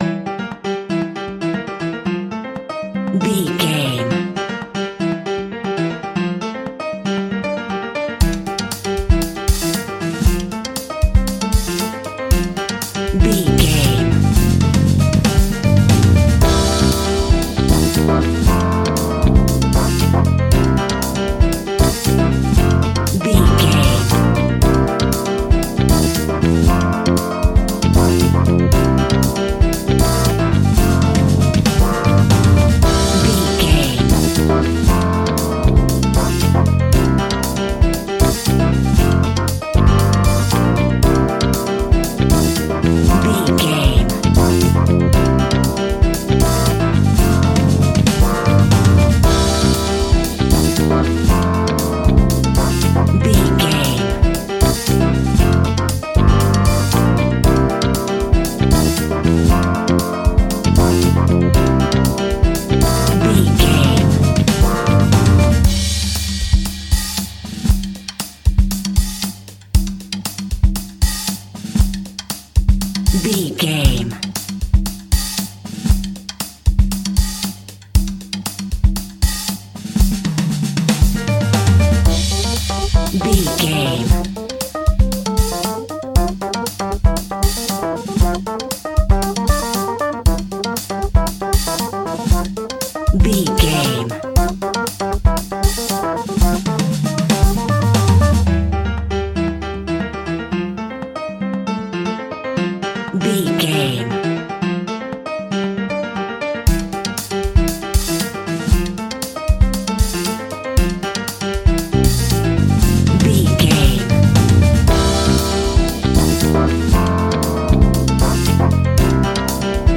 Ionian/Major
flamenco
romantic
maracas
percussion spanish guitar